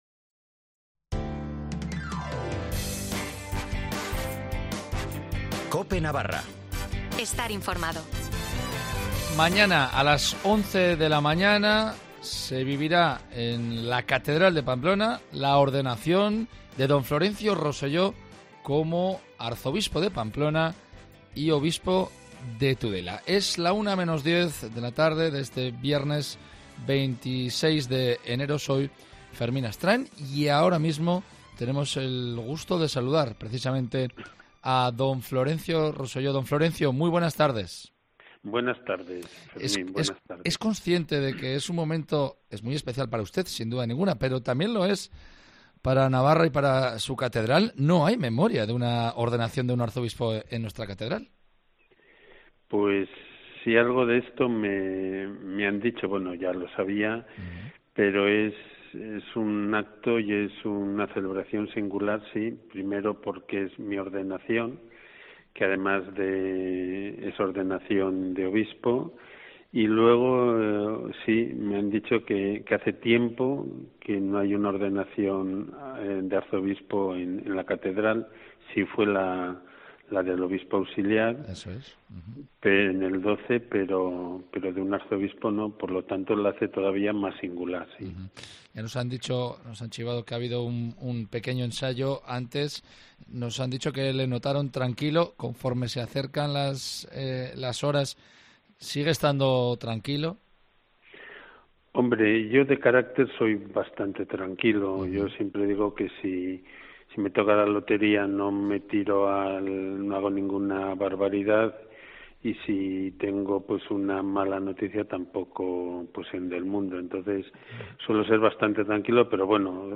Entrevista al mercedario Don Florencio Roselló, que mañana será ordenado Arzobispo de Pamplona y obispo de Tudela en la Catedral de Pamplona
Entrevista a Florencio Roselló